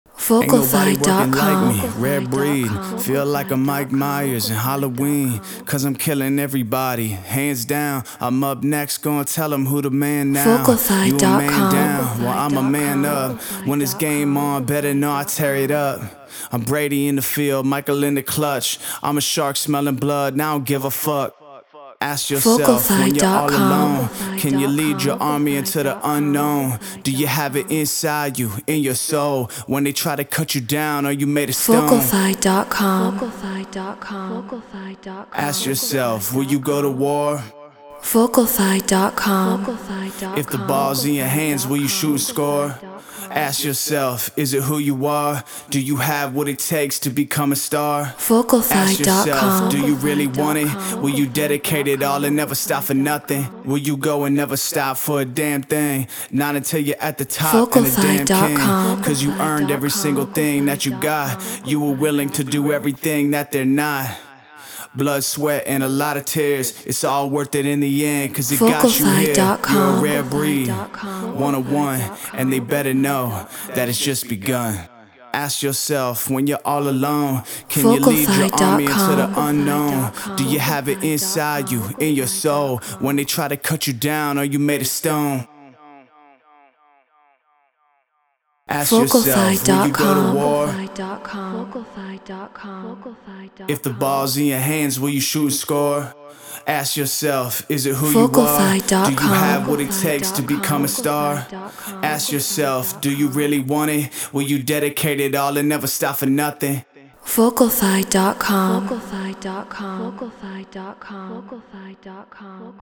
Non-Exclusive Vocal.